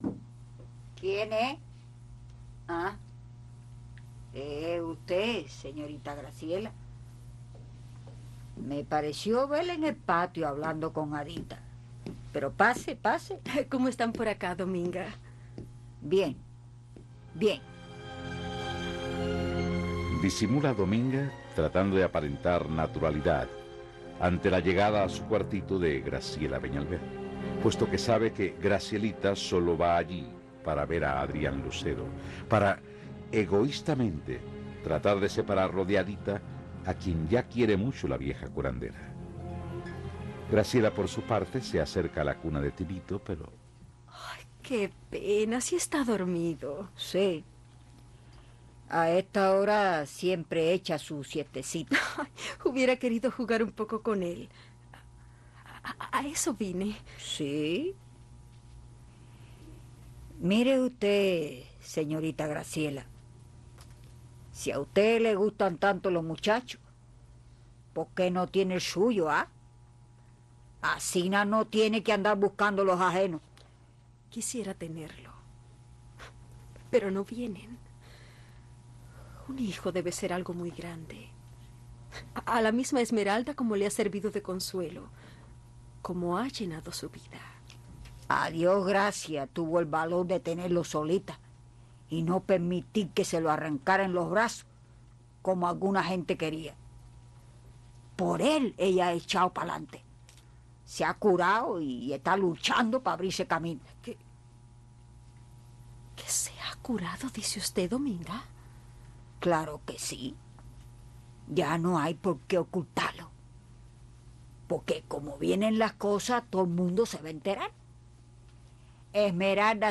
Protagonizan Lupita Ferrer